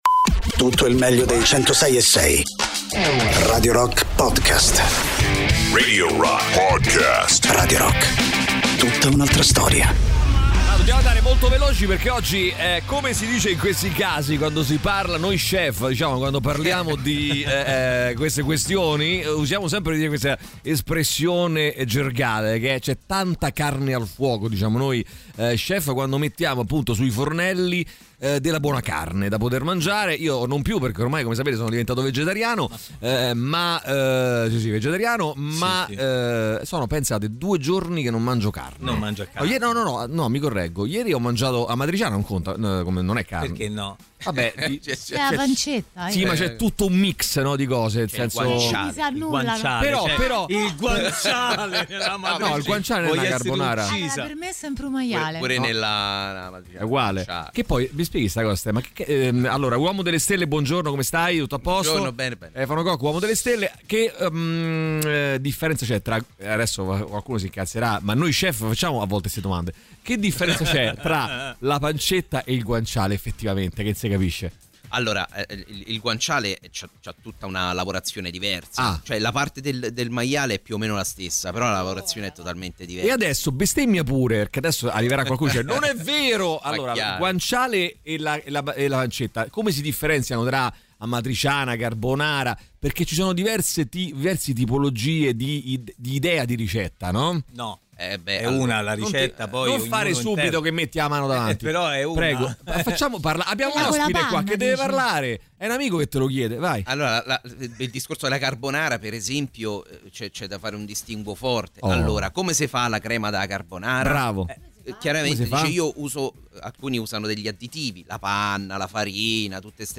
sui 106.6 di Radio Rock